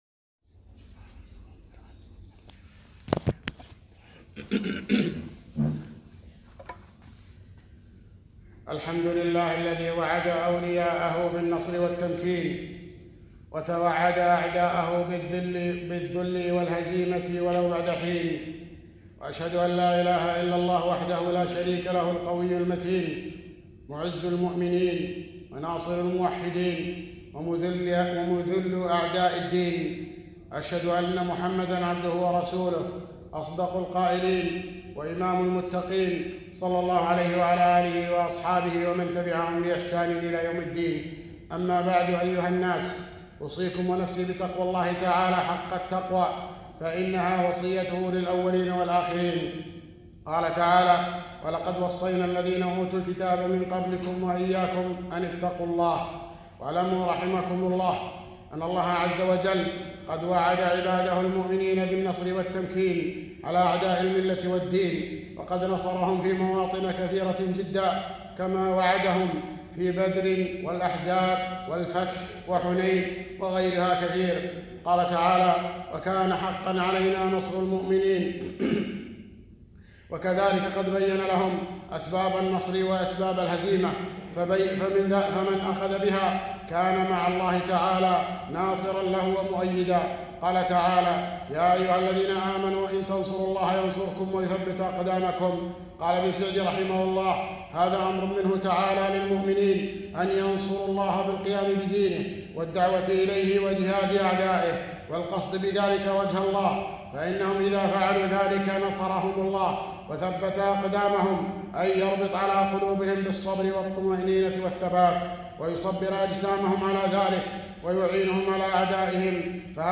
خطبة - أسباب النصر والتمكين